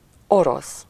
Ääntäminen
France: IPA: [ʁys]